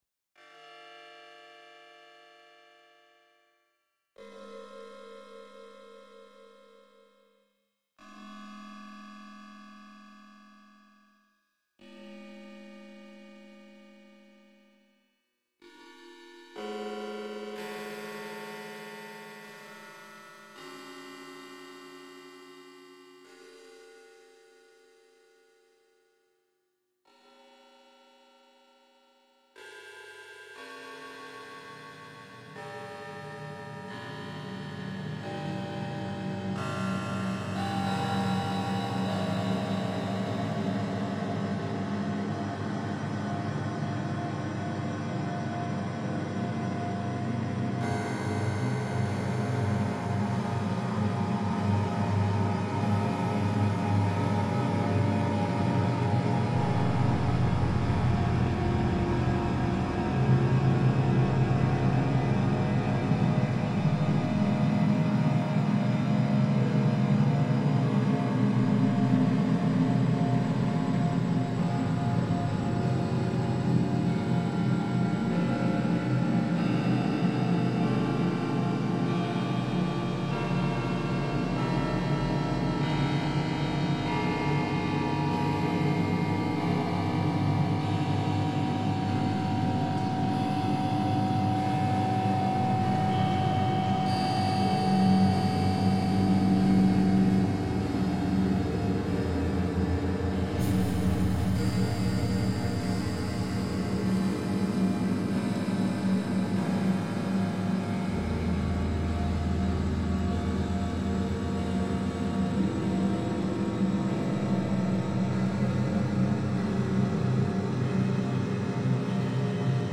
Westminster Abbey tones reimagined